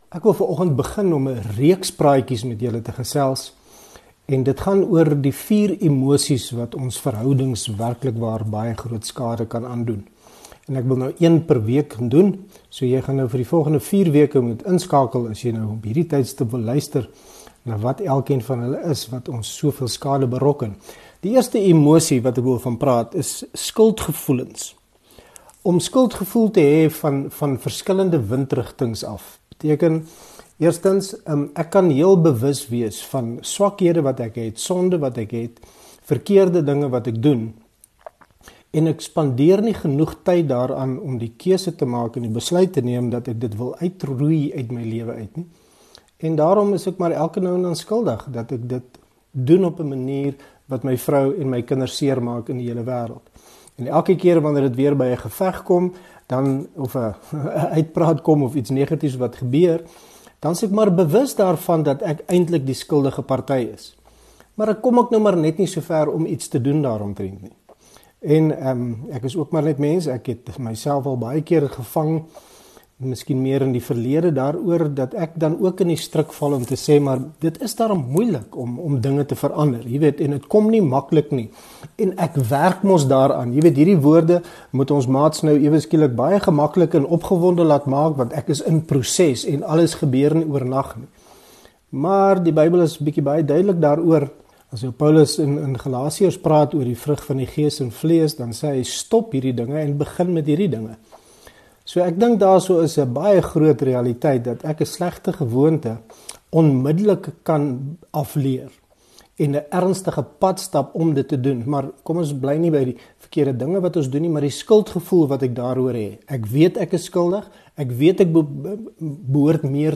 It is packed with humour, information and testimonies.